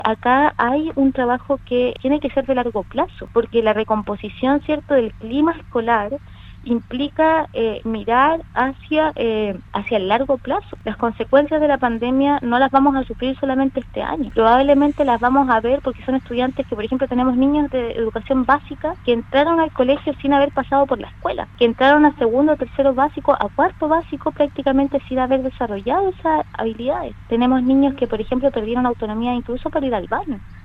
La Seremi de Educación, Daniela Carvacho, detalló que se trata de un trabajo a largo plazo.